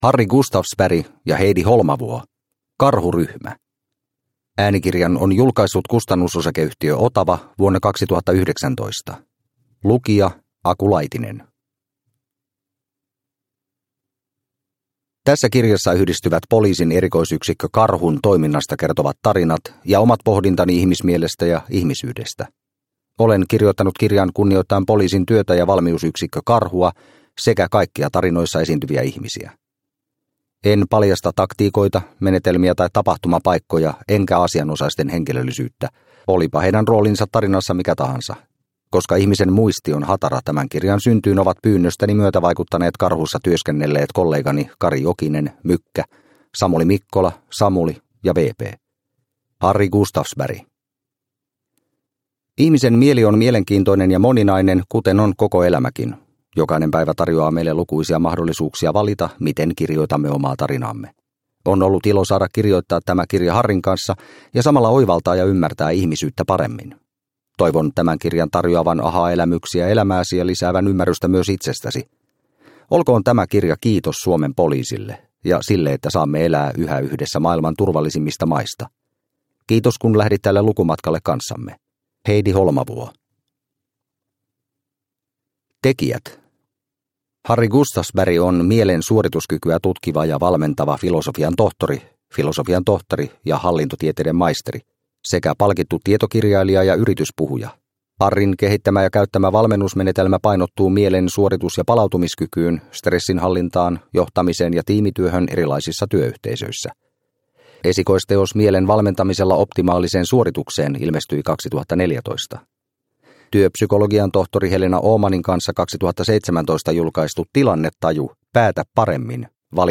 Karhuryhmä – Ljudbok – Laddas ner